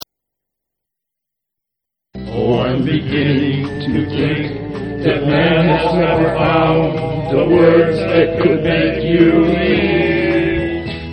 Rock and Roll classics